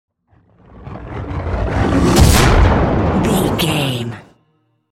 Creature whoosh to hit large
Sound Effects
Atonal
ominous
eerie
woosh to hit